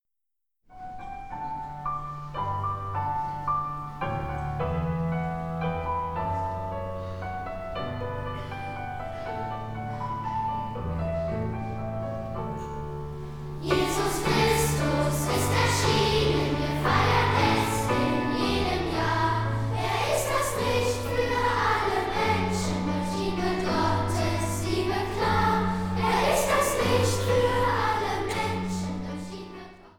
Streicher